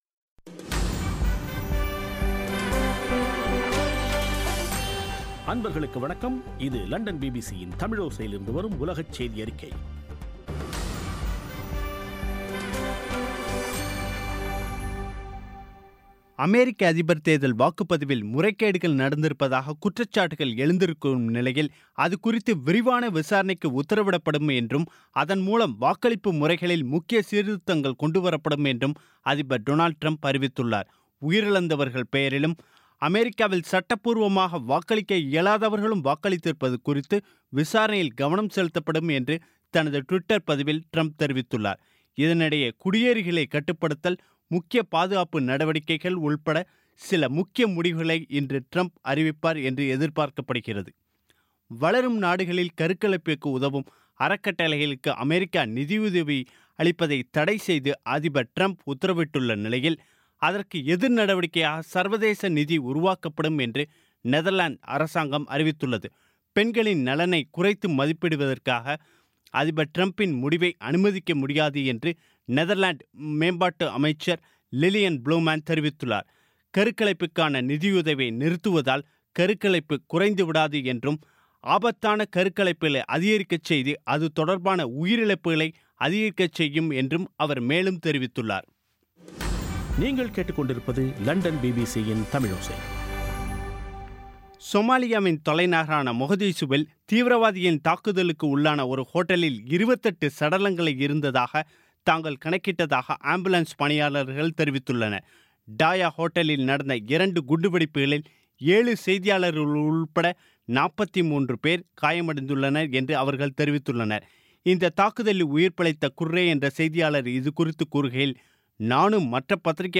பிபிசி தமிழோசை செய்தியறிக்கை (25/01/2017)